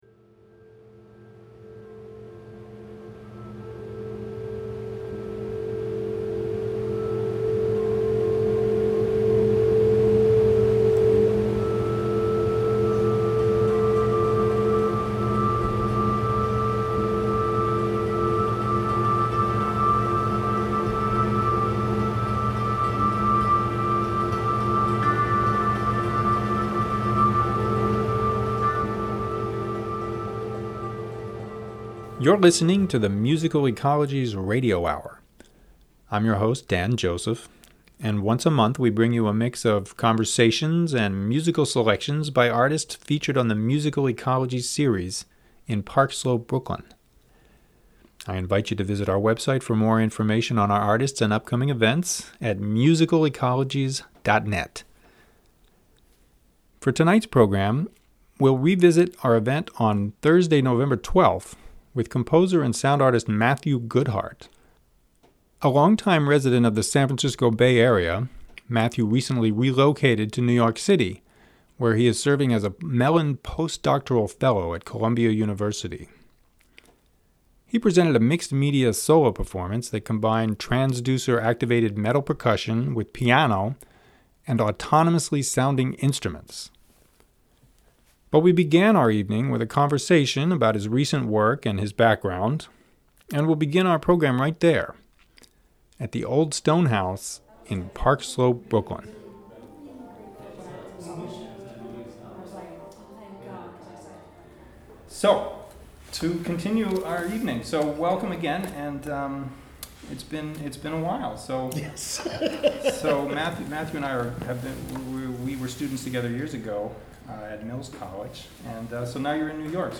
The Musical Ecologies Radio Hour is a monthly program that features conversations, live concert recordings, and other audio excerpts featuring artists presented on the Musical Ecologies series in Park Slope, Brooklyn.